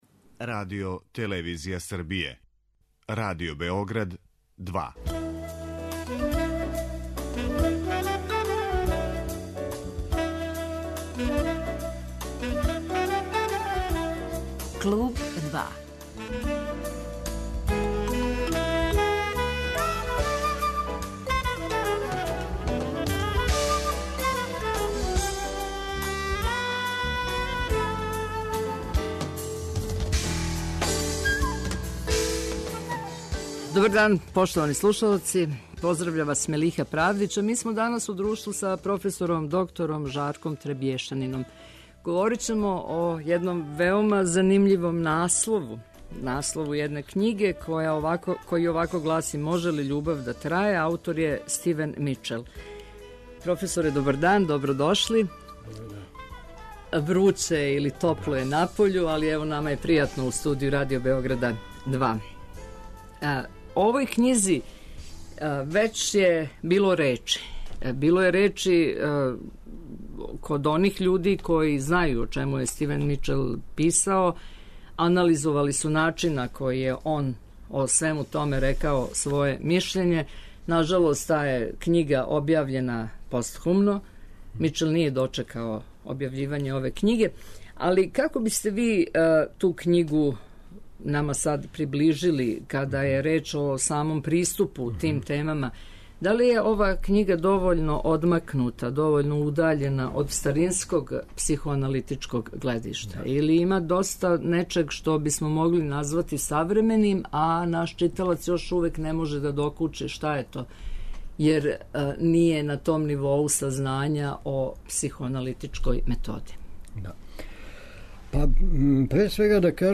психолог.